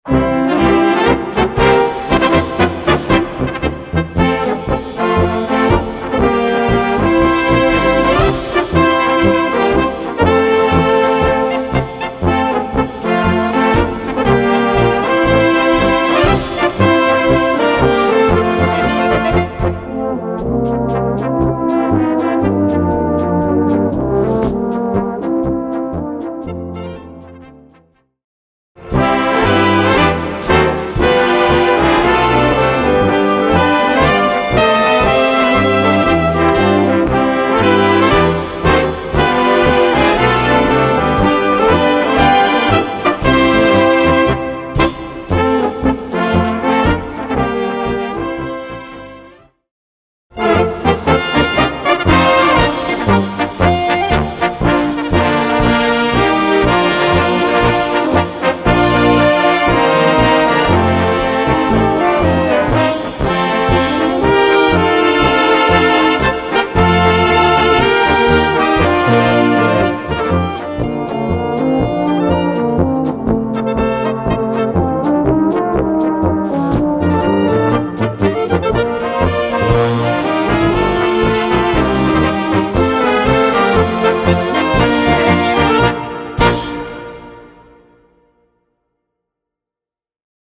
Gattung: Polka mit Text
Besetzung: Blasorchester